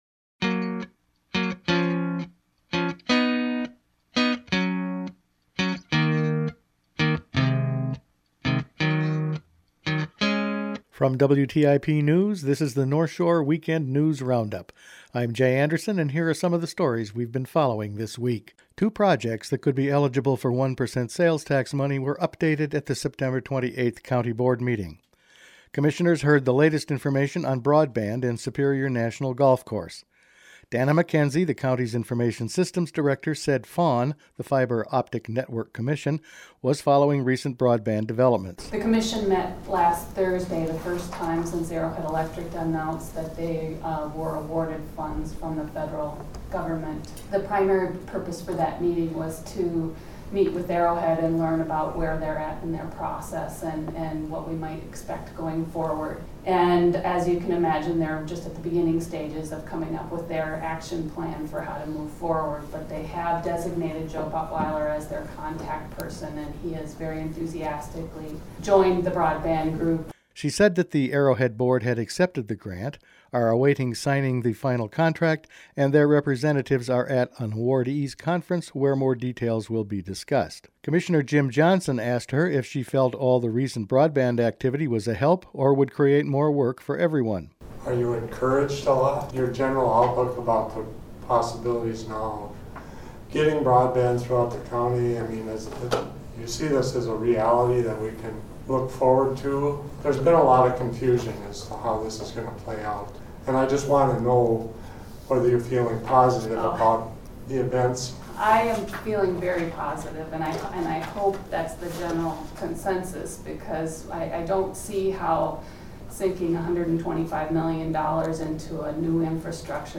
Each weekend the WTIP News Department assembles the week’s important news stories to play here on North Shore Weekend. Among other things, this week we’ve been covering highway progress, wolves and updates on broadband and the golf course.